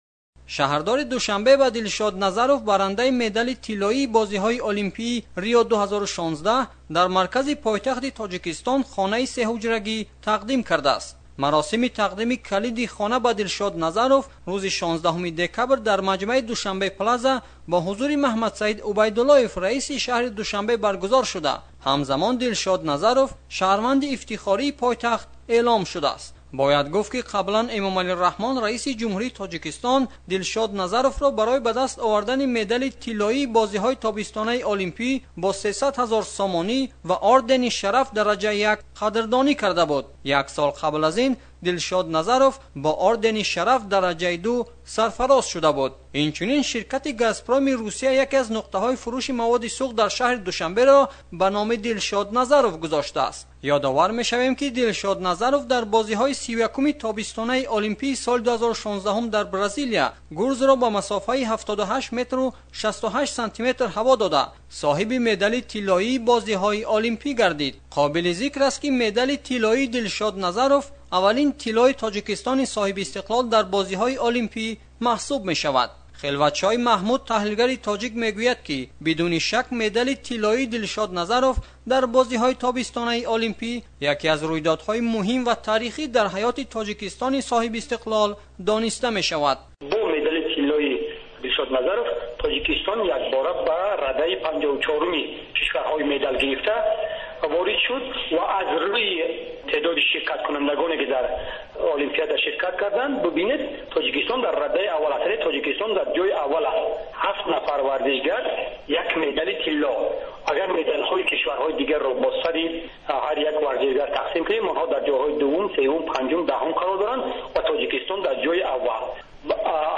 гузориш